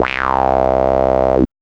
0505R ACIDSY.wav